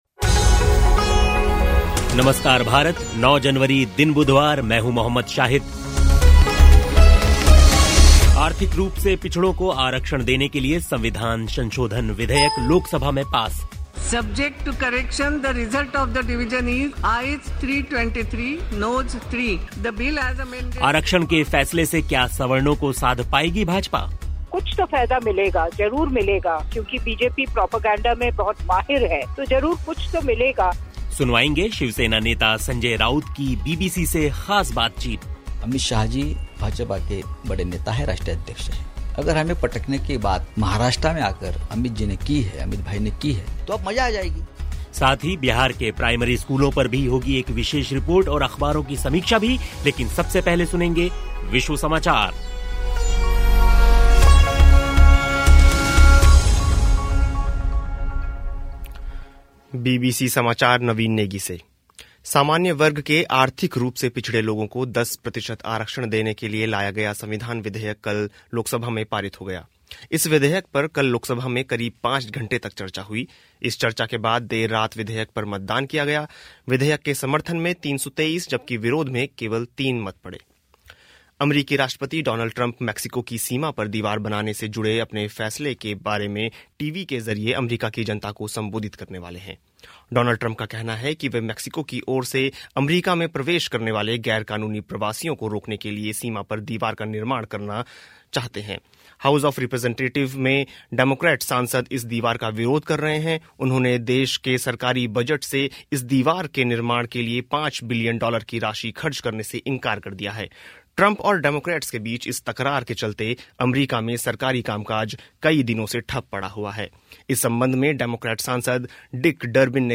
शिवसेना नेता संजय राउत की बीबीसी से ख़ास बातचीत. साथ ही बिहार के प्राइमरी स्कूलों पर भी एक विशेष रिपोर्ट सुनिए.